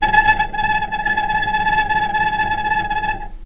vc_trm-A5-pp.aif